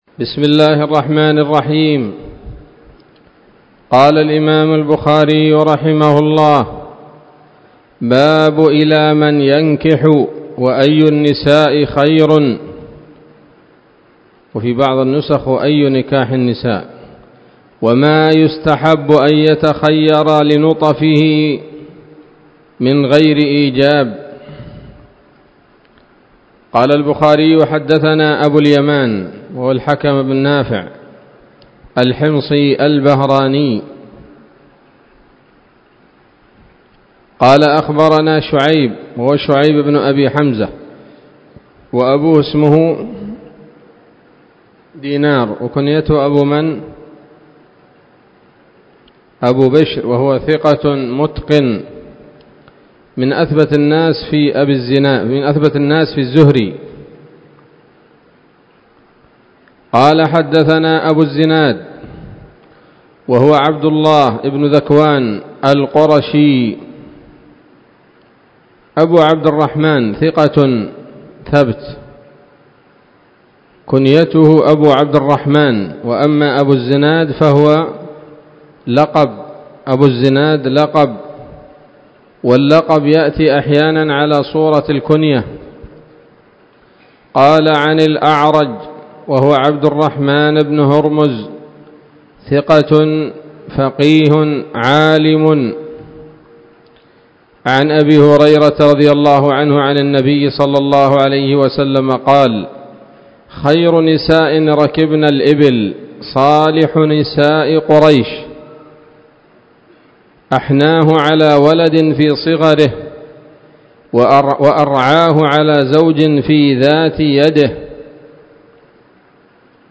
الدرس الحادي عشر من كتاب النكاح من صحيح الإمام البخاري